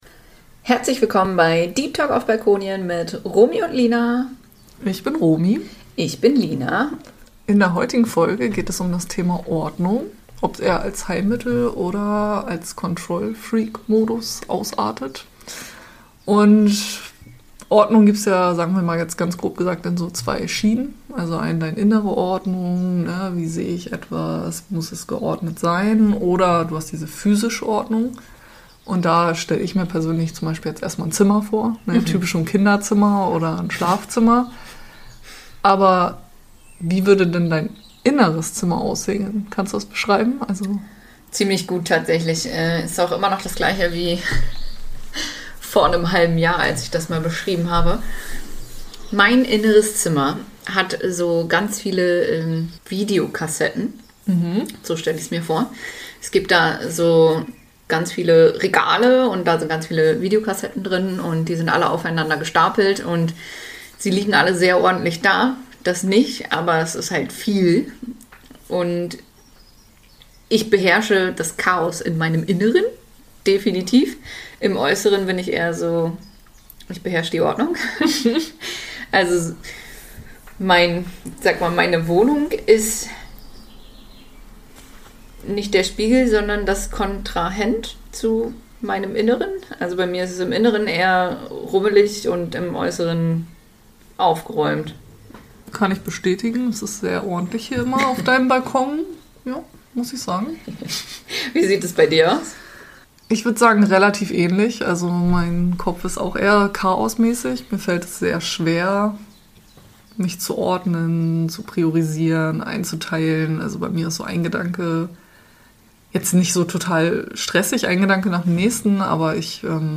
Ein liebevolles Gespräch über Kontrolle, Loslassen, To-do-Stapel und das innere Bedürfnis nach Übersicht.